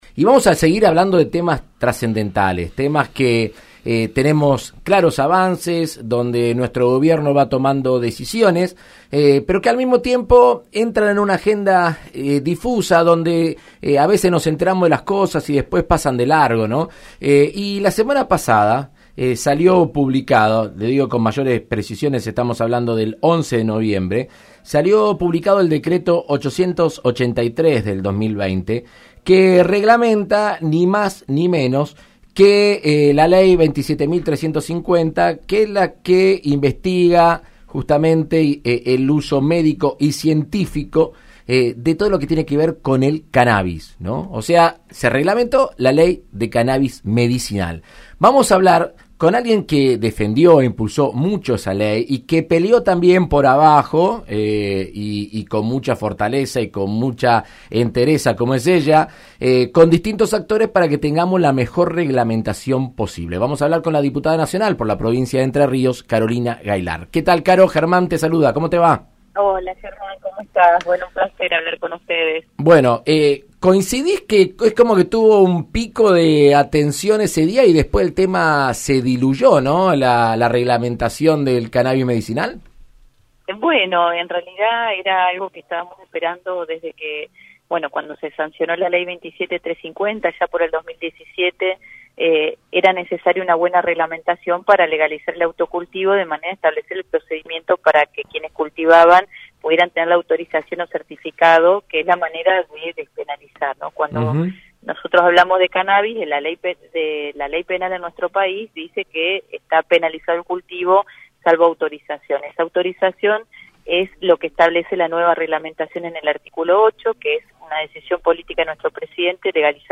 La diputada nacional Carolina Gaillard explicó en diálogo con su compañero de bancada Germán Martínez en Argentina Unida Contra el Coronavirus qué implican los cambios introducidos por el gobierno de